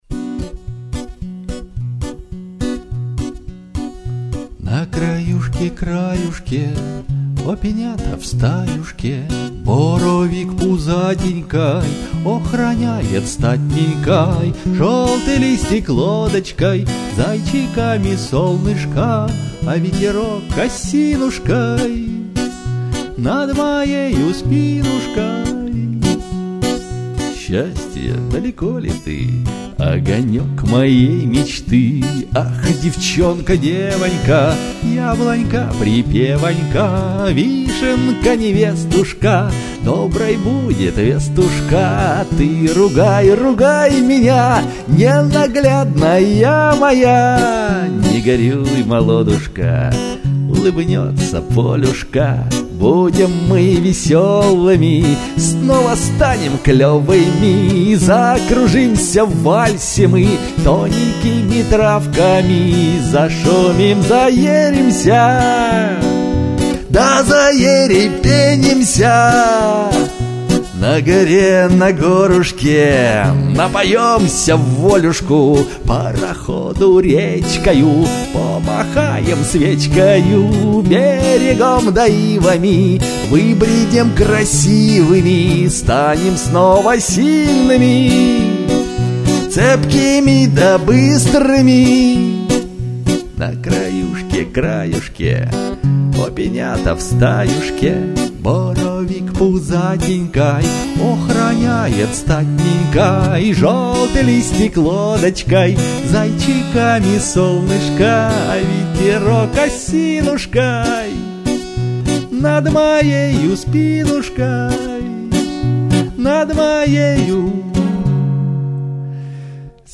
• Песня: Лирика
Песня под гитару